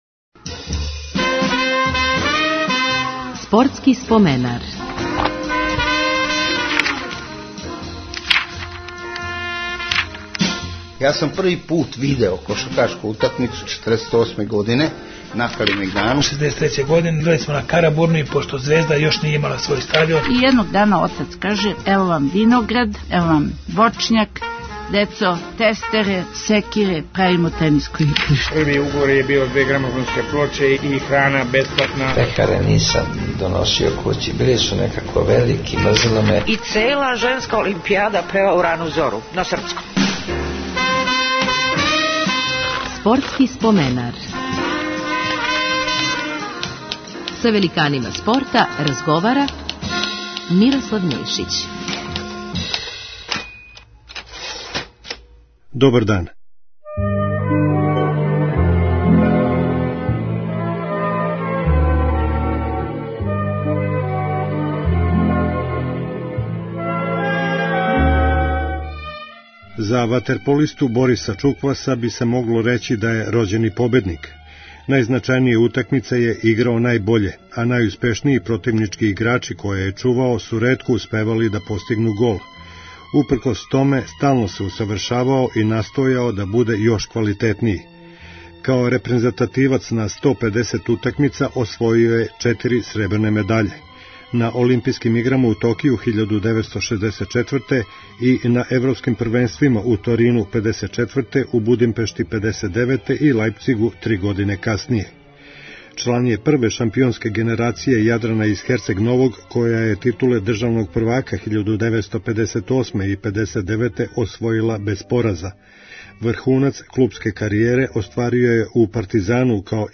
Радио Београд 1 од 16 до 17 часова.